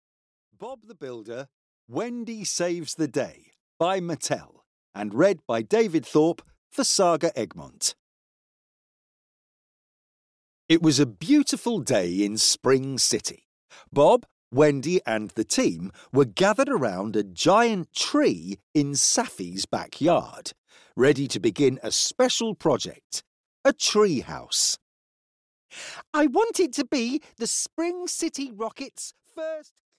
Audio knihaBob the Builder: Wendy Saves the Day (EN)
Ukázka z knihy